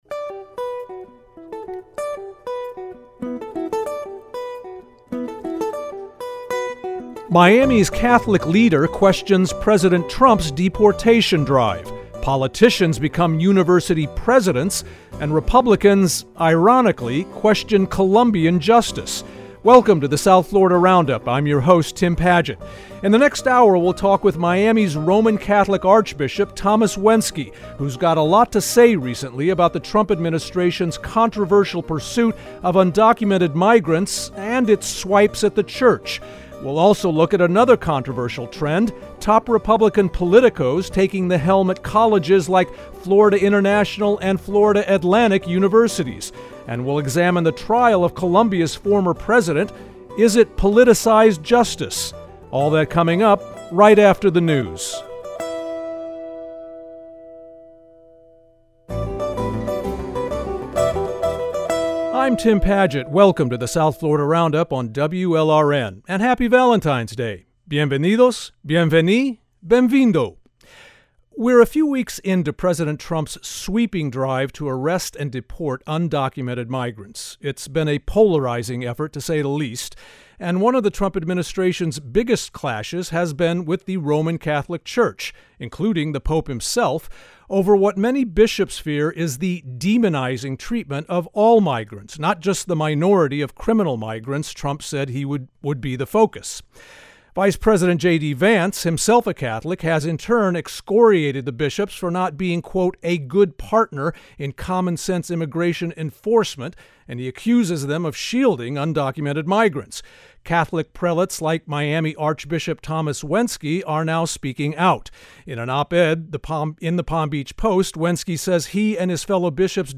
Each week, journalists and newsmakers from South Florida analyze and debate some of the most topical issues from across the region.